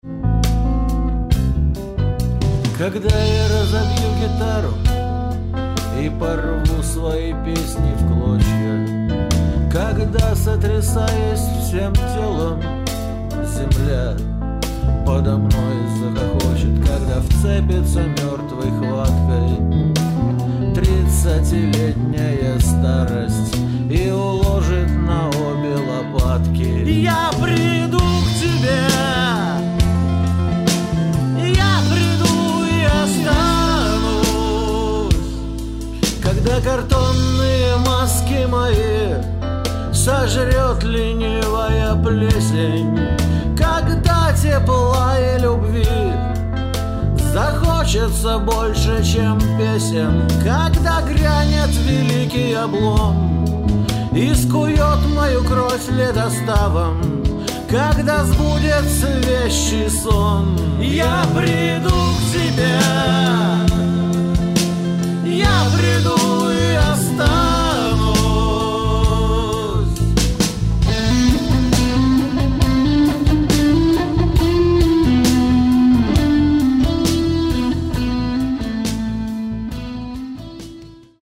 Лирические звуки клавиш
Это конечно "русский рок".